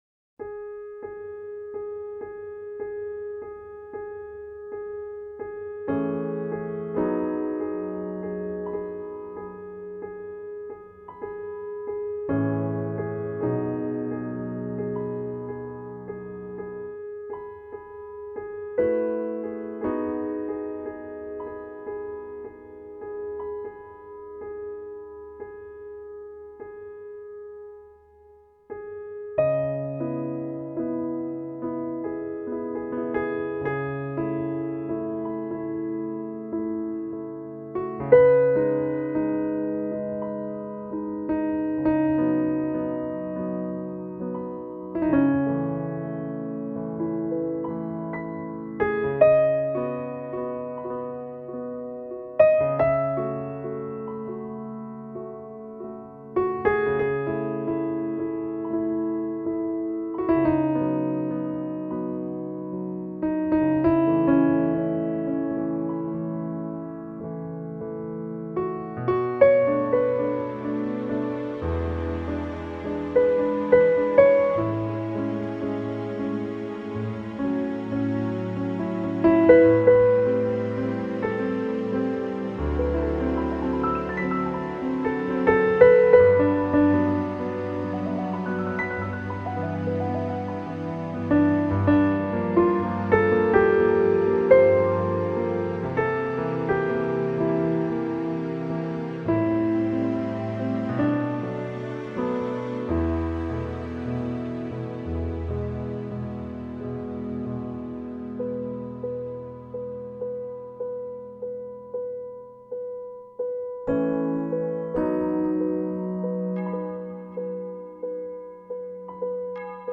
Классическая музыка Инструментальная музыка Классика